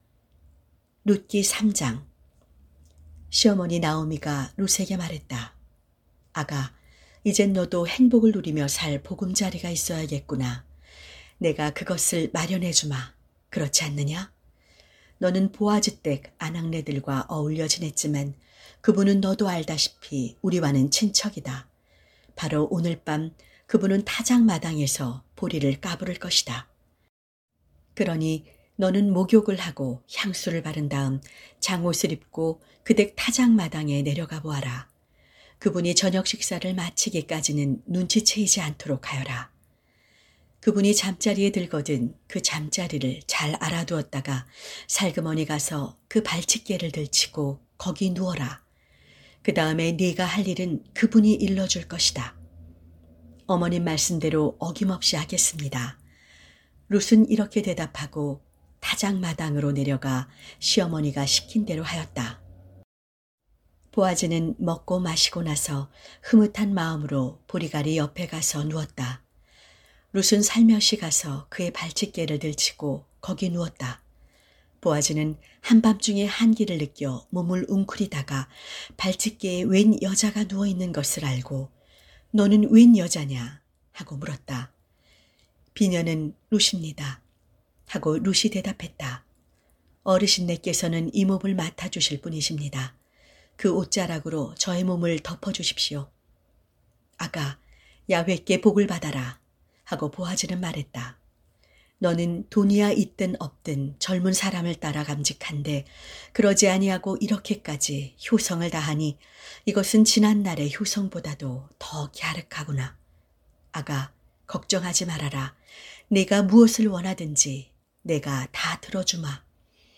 성경 오디오